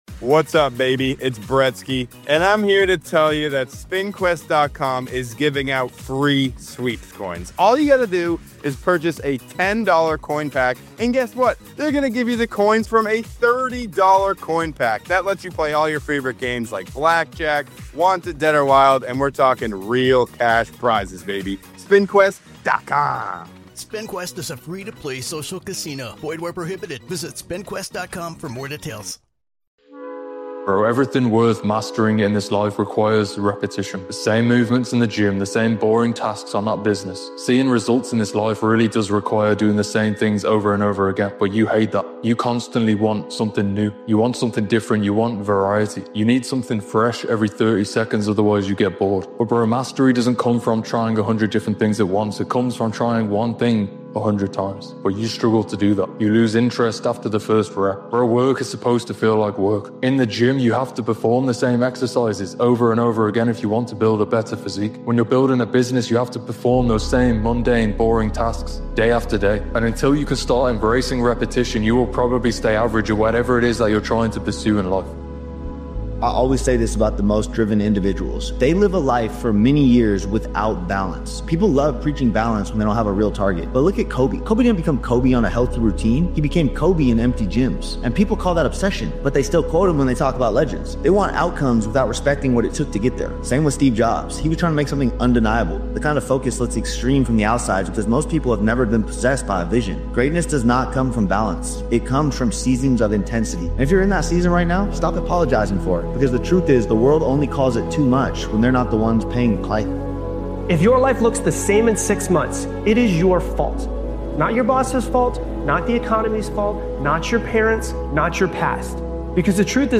This powerful motivational speech by Daily Motivations is about trusting yourself when it matters most. It focuses on discipline, consistency, self-belief, and the mindset required to keep going when doubt shows up.